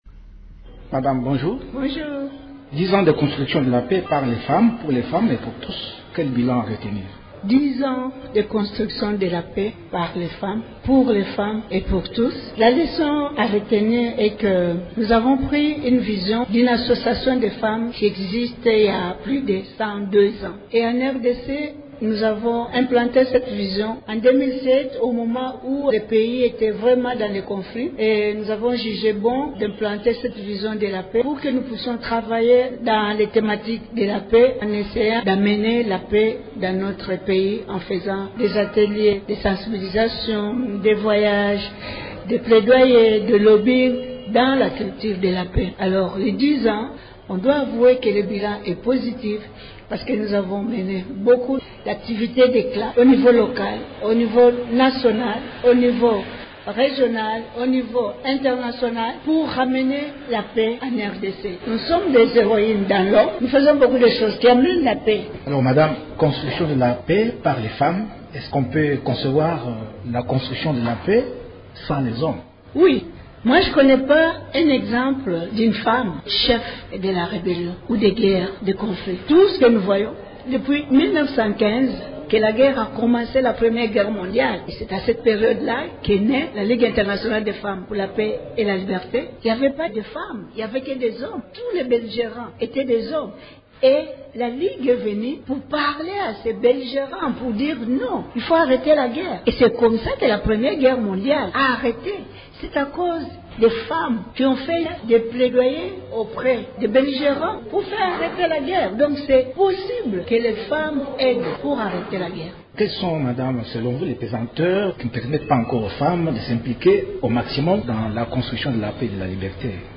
s’entretient avec